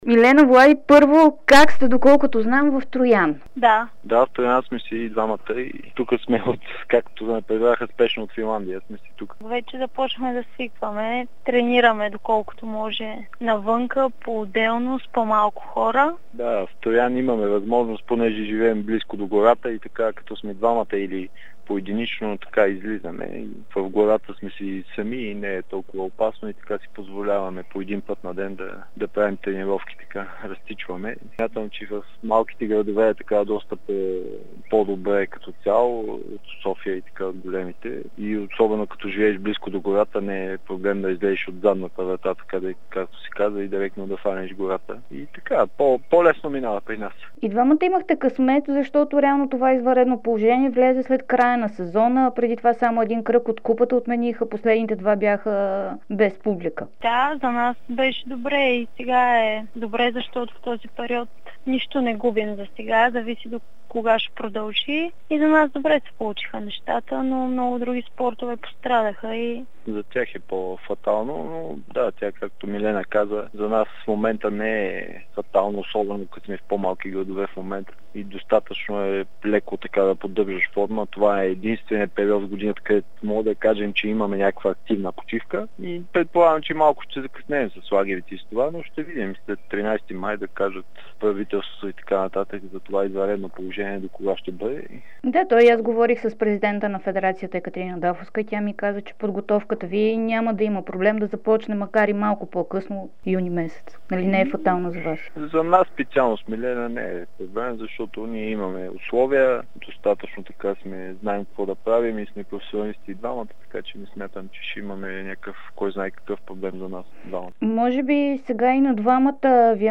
Звездната двойка на българския биатлон, а и на спорта ни като цяло Владимир Илиев и Милена Тодорова дадоха специално интервю за Дарик радио и dsport.